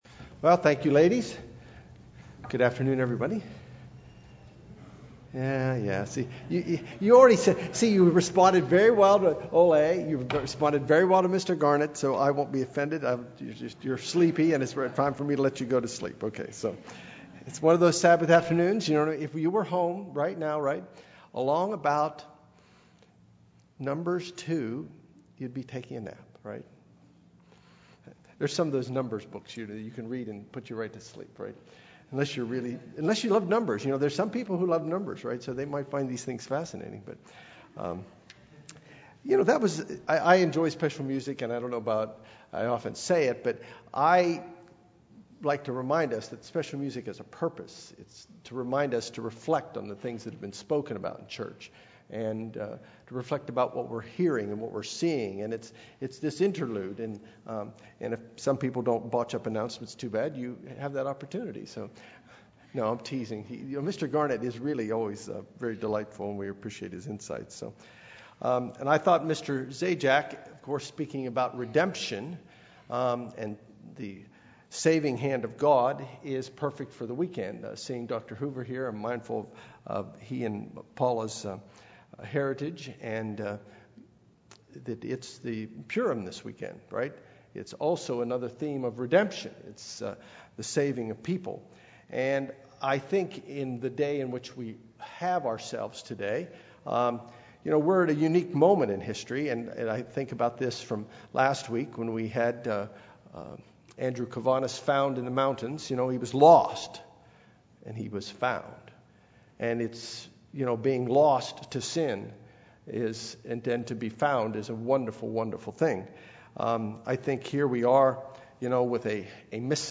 Given in Los Angeles, CA
This message considers His mission of salvation through the following parables: - The Heavenly Banquet - The Cost of Discipleship - The Lost Coin - The Lost Sheep UCG Sermon Studying the bible?